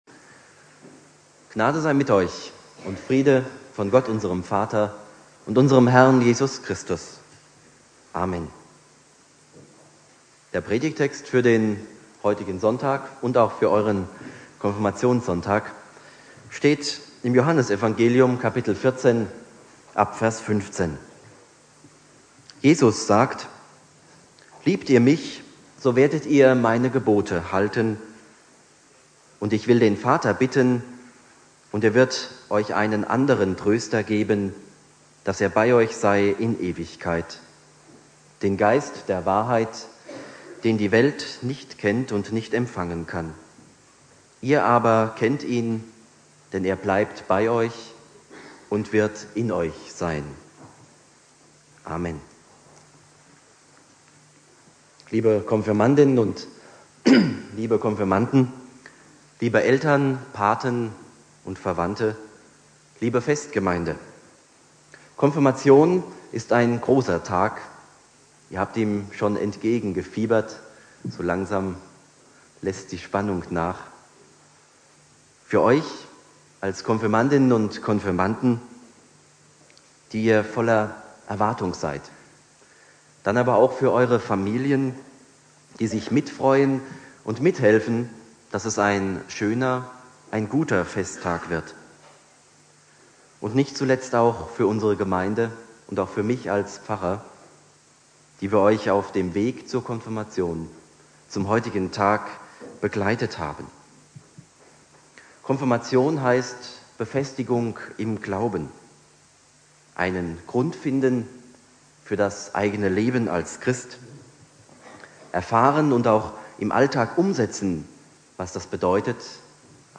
Predigt
Thema: Ein Testament Jesu an seine Jünger (Konfirmation Hausen)